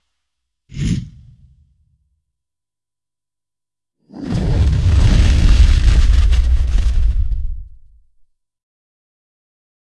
Рычание зомби: